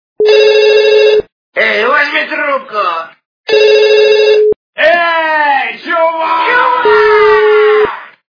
» Звуки » Смешные » Страшный голос - Эй, возьми трубку, эй, чув-а-к!!
При прослушивании Страшный голос - Эй, возьми трубку, эй, чув-а-к!! качество понижено и присутствуют гудки.